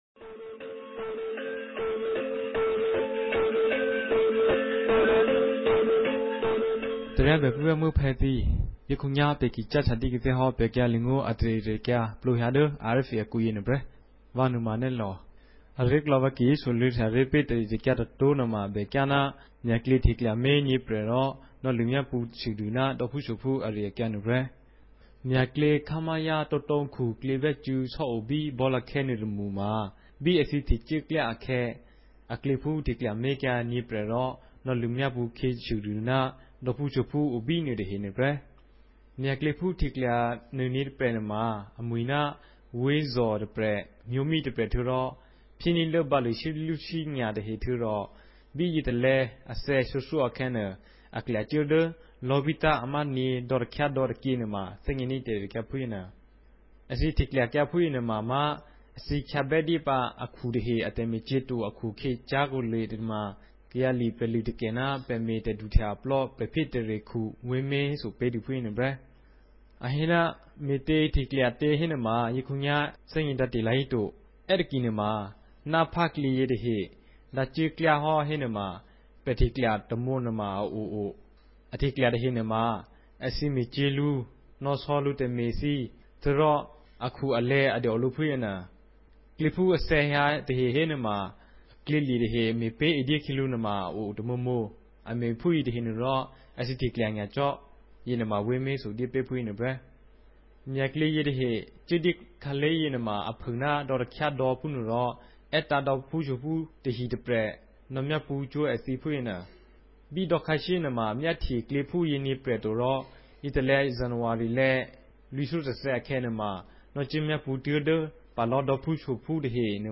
ကရင်နီဘာသာ အသံလြင့်အစီအစဉ်မဵား